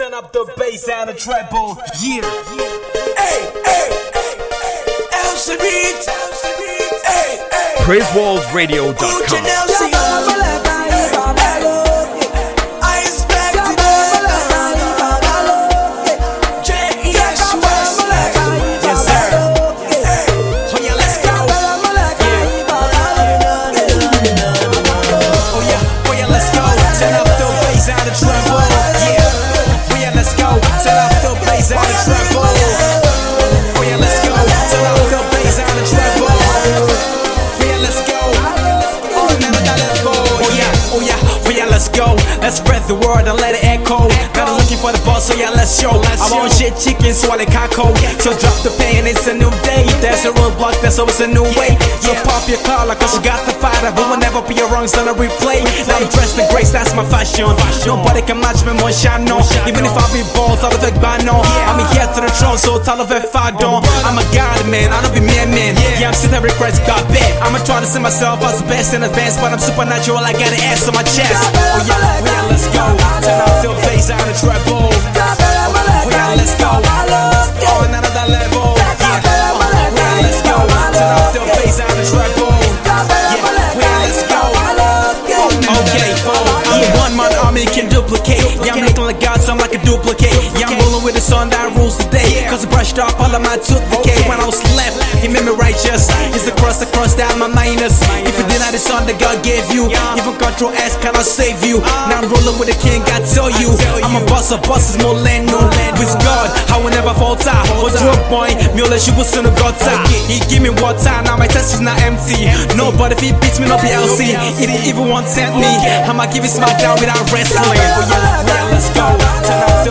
gospel hip hop
The groovy praise track (which is a remix